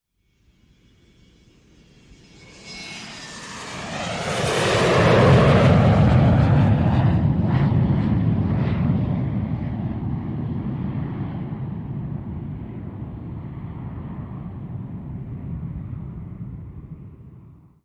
Jet; Large Commercial; Take Off with Some Engine Whine, By, Long Away